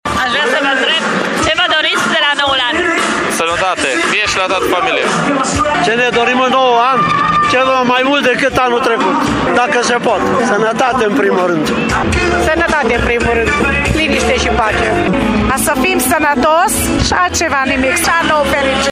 Târgumureșenii au venit, cu mic cu mare, să asiste la spectacol încă de la zece și jumătate și spun că pentru noul an își doresc cel mai mult sănătate și liniște: